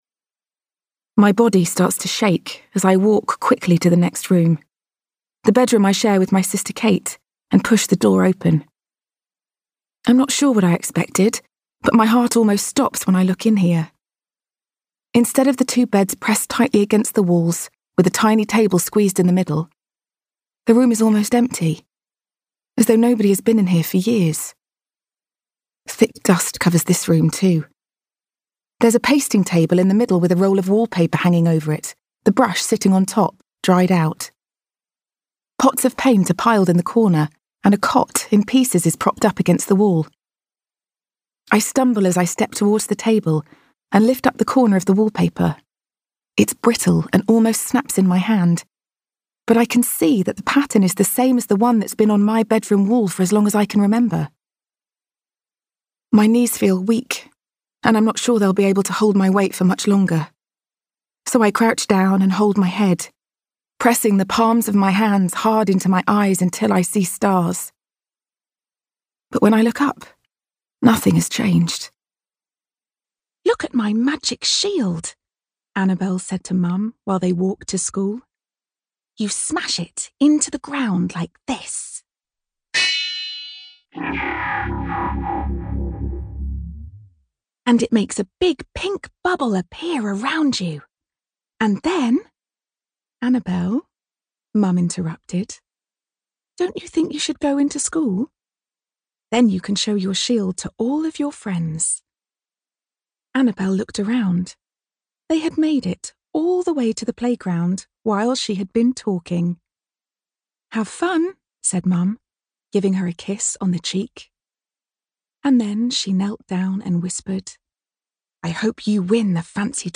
Audio Book/Audio Drama
Standard English/RP, American, London/Cockney, Mid-Atlantic, Northern (English), Irish, Liverpudlian
Actors/Actresses, Corporate/Informative, Natural/Fresh, Smooth/Soft-Sell, Understated/Low Key, Character/Animation, Upbeat/Energy